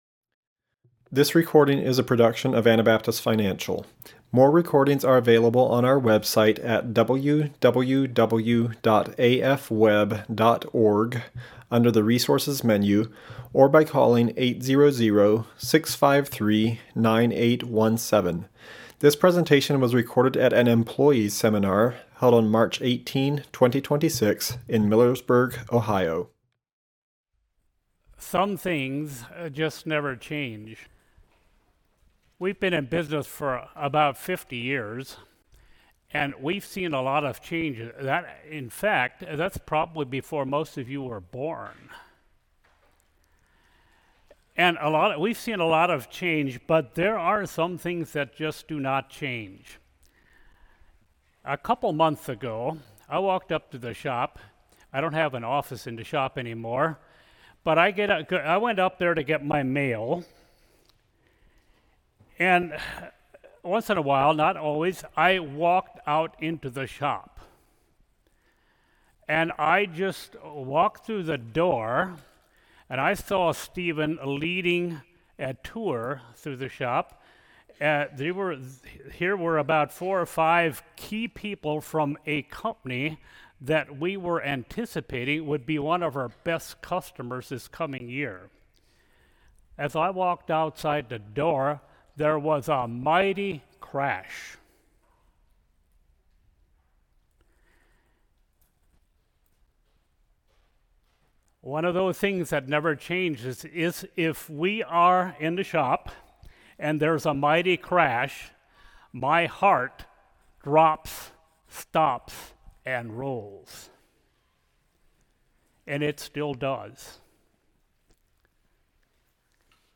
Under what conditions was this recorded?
Ohio Employee Seminar 2026